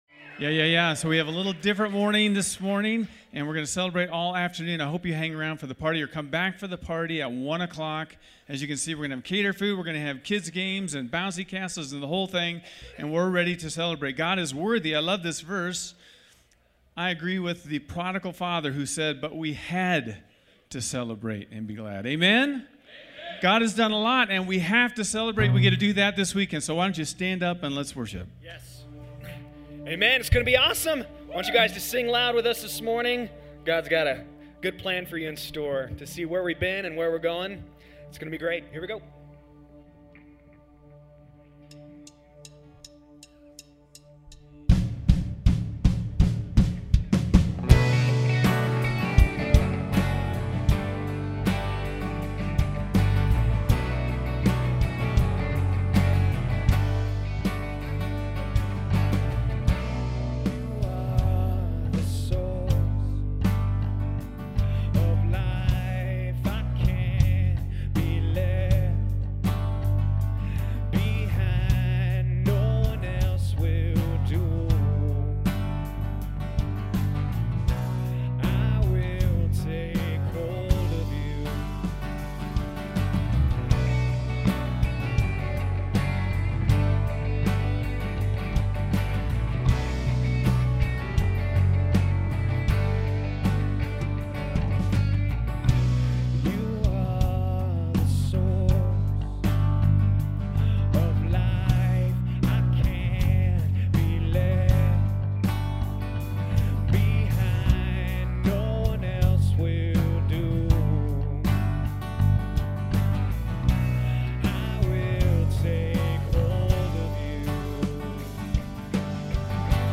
This unforgettable celebration service commemorates the goodness of God and motivates us for the future. Looking back in awe and looking forward with anticipation through stories, worship and honor — this gives us an opportunity to trust God for another 20 years of fruitful labor.